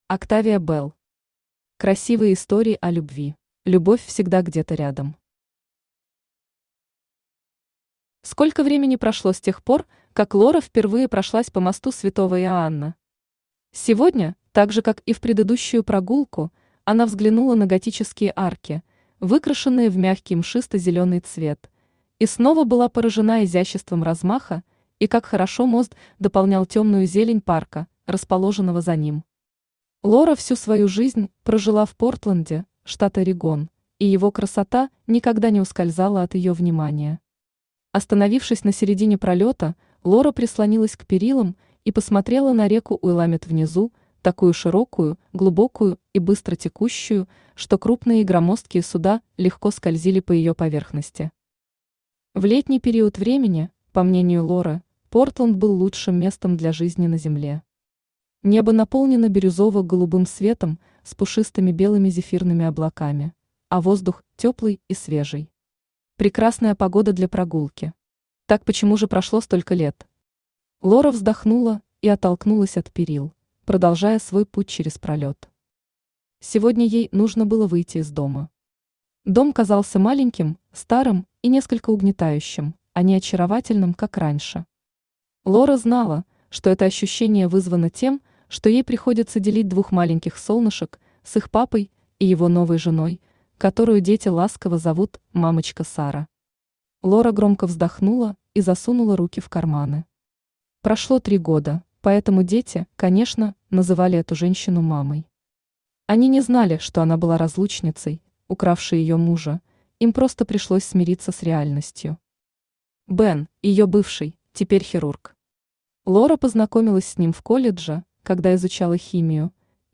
Аудиокнига Красивые истории о любви | Библиотека аудиокниг
Aудиокнига Красивые истории о любви Автор Октавия Белл Читает аудиокнигу Авточтец ЛитРес.